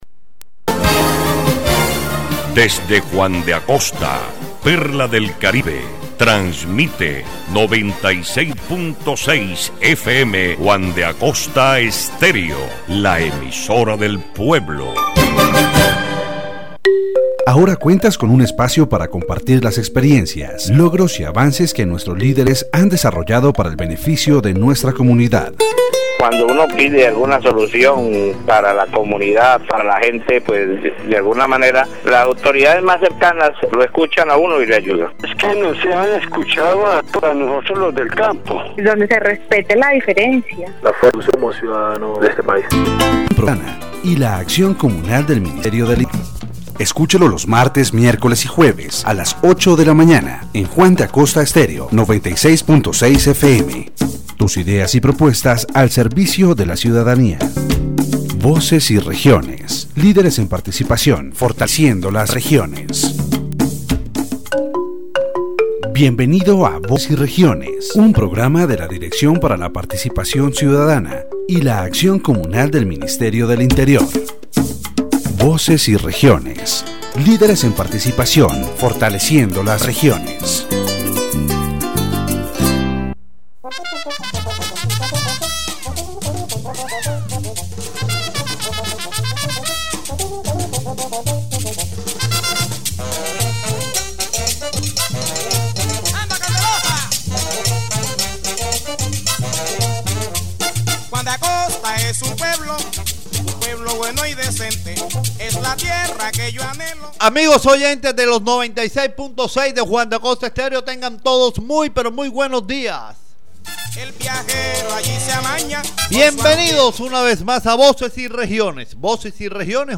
The program "Voices and Regions", broadcast on Juan de Acosta Estéreo 96.6 FM, is a space created by the Ministry of the Interior and the Francisco José de Caldas District University. This episode focused on citizen participation and peace in Colombia, emphasizing the role of civil society in peace processes and the impact of the Victims and Land Restitution Law.
The interviewees debated forced displacement in Colombia, the organization of victims in Juan de Acosta, and the need to ensure citizen participation in decision-making.